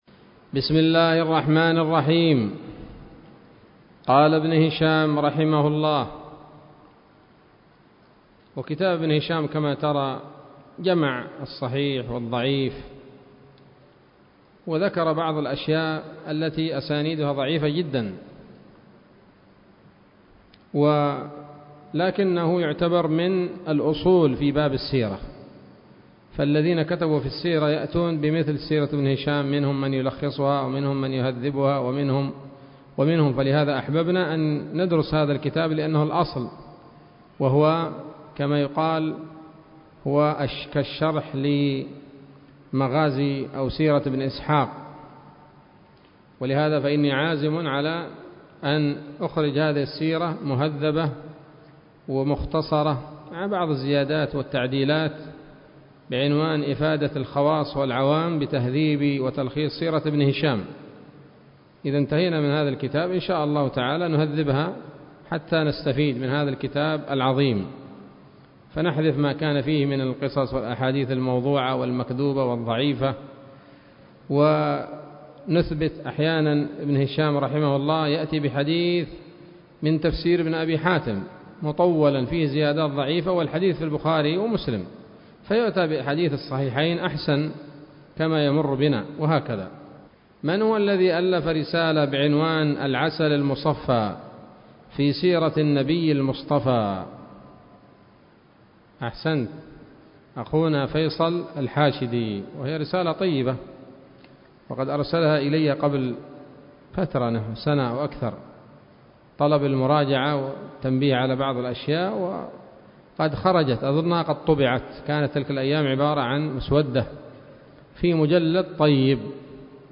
الدرس الثامن والأربعون من التعليق على كتاب السيرة النبوية لابن هشام